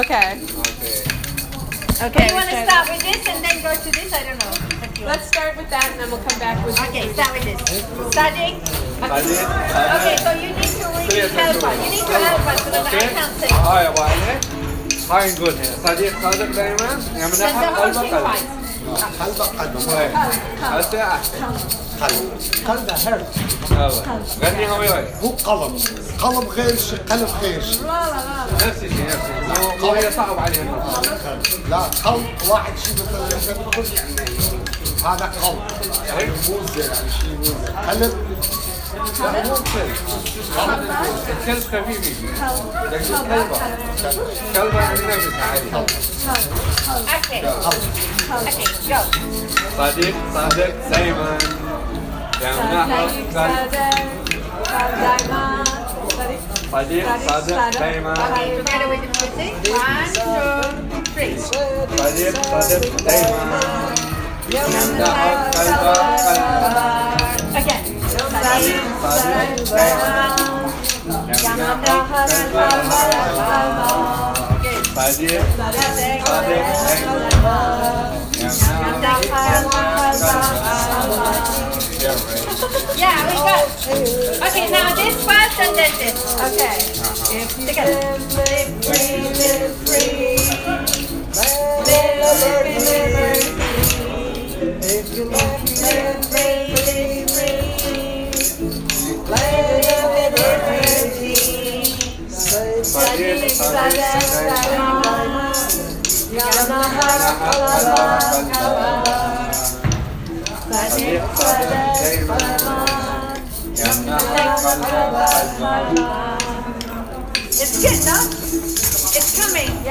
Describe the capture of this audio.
These are recordings from the center that show the process of writing the song: liberty-song-beginnings.m4a